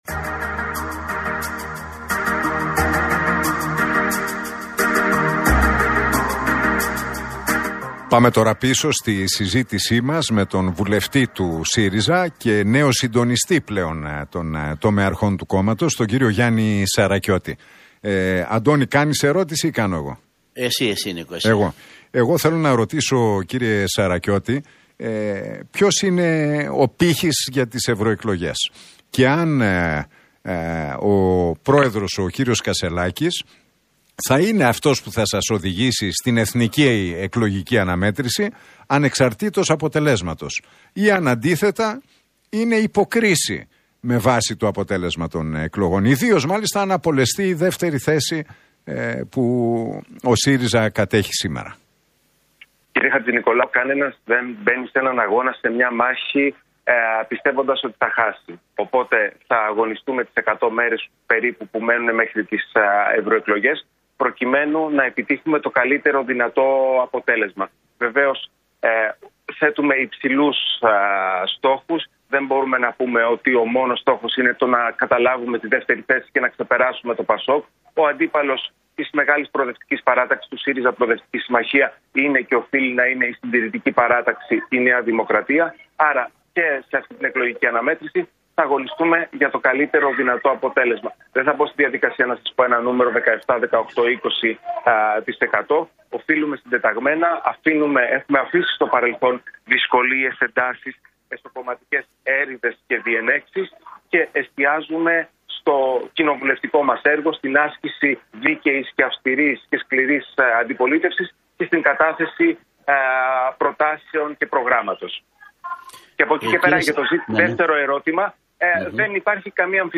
Ο βουλευτής του ΣΥΡΙΖΑ, Γιάννης Σαρακιώτης